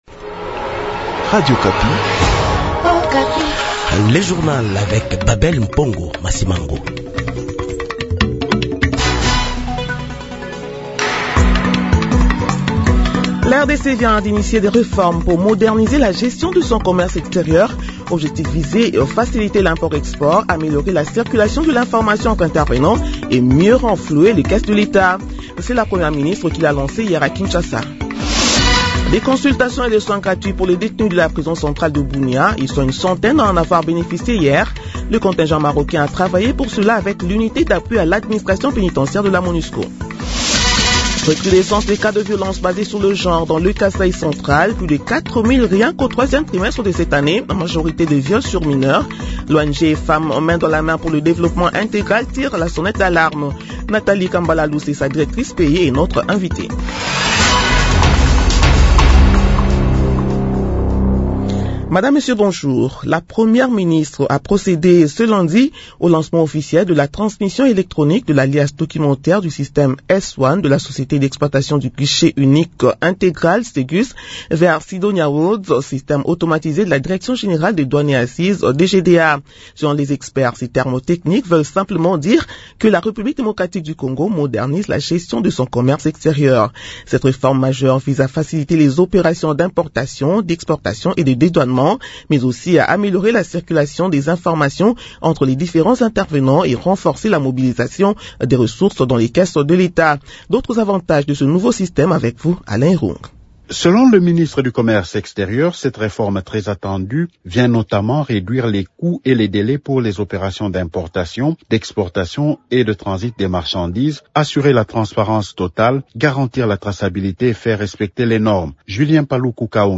Journal 7h de ce mardi 30 décembre 2025